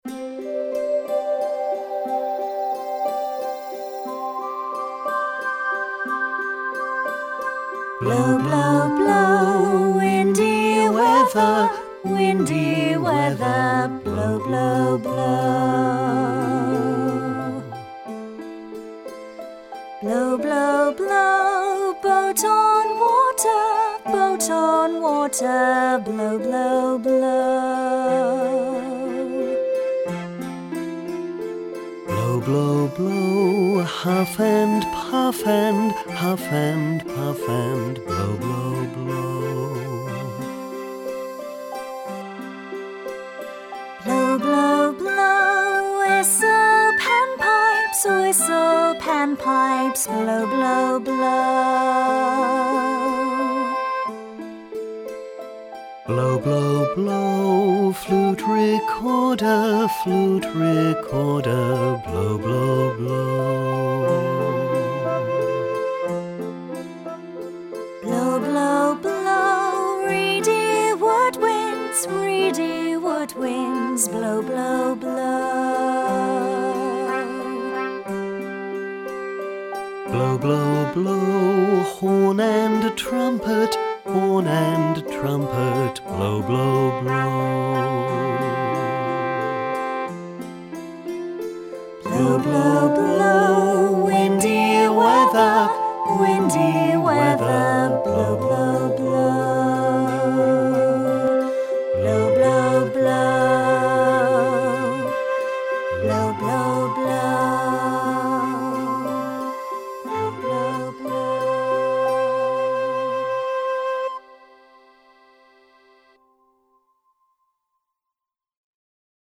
The notes used are low A, C, D, E, G, A, and high C.
Guide vocal